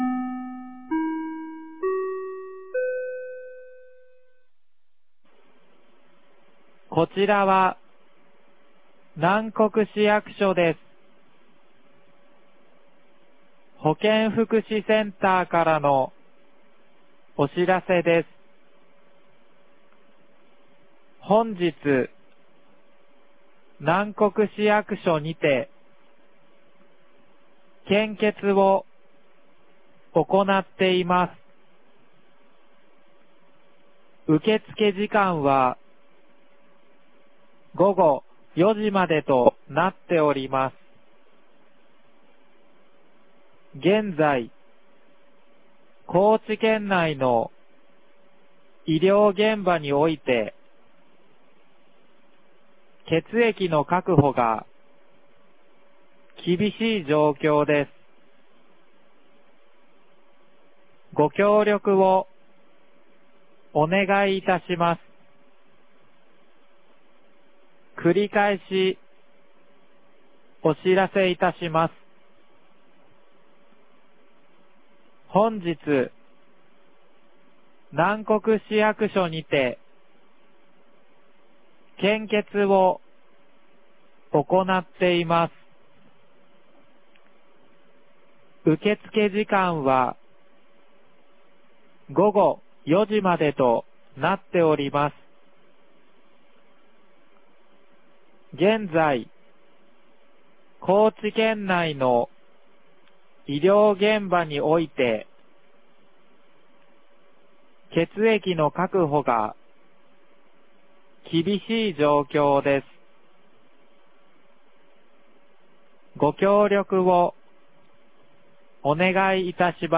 南国市放送内容
2025年01月28日 13時02分に、南国市より放送がありました。